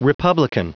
Prononciation audio / Fichier audio de REPUBLICAN en anglais
Prononciation du mot republican en anglais (fichier audio)